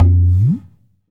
TABLA GAE -S.WAV